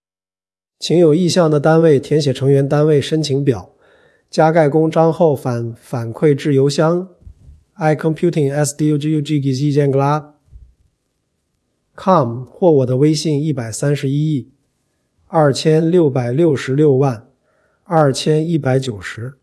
f5tts - F5-TTS wrap module